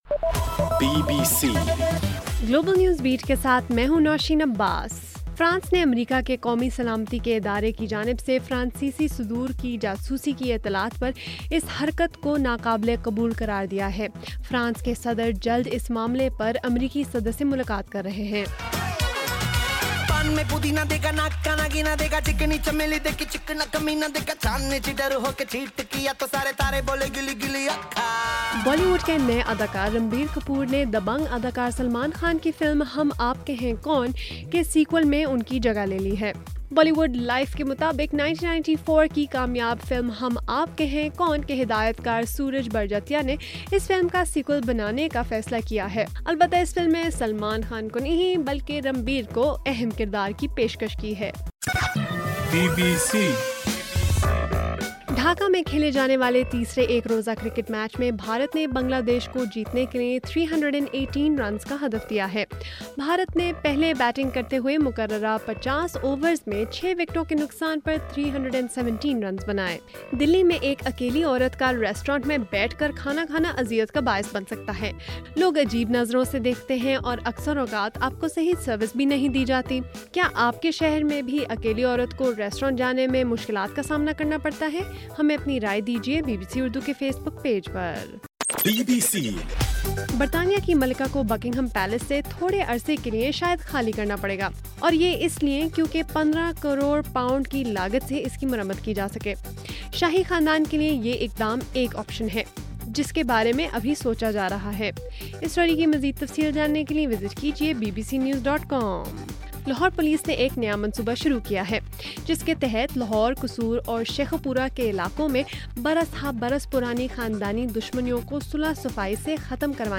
جون 24: رات 11 بجے کا گلوبل نیوز بیٹ بُلیٹن